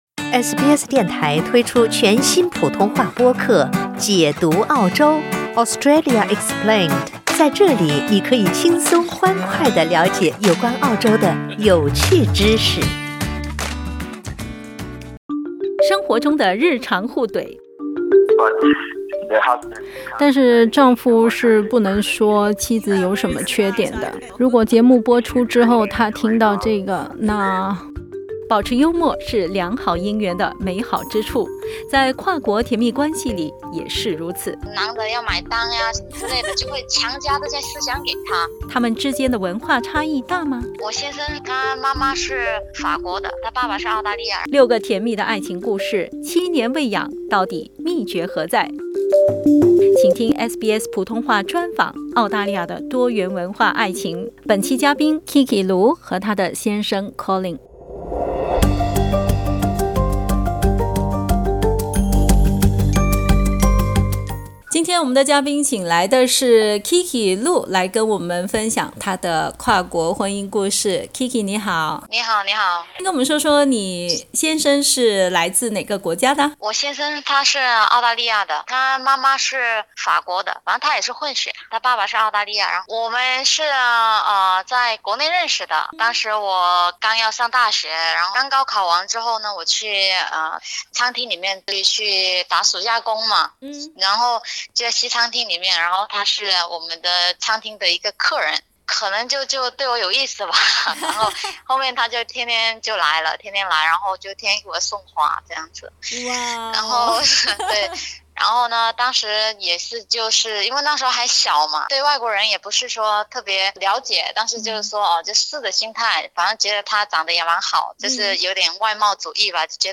六个甜蜜的爱情故事，七年未痒，到底秘诀何在？SBS普通话专访 《澳大利亚的多元文化爱情》为你解密。